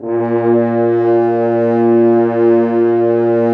Index of /90_sSampleCDs/Roland LCDP06 Brass Sections/BRS_F.Horns 2 _/BRS_FHns Dry _